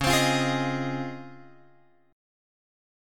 C# Major 9th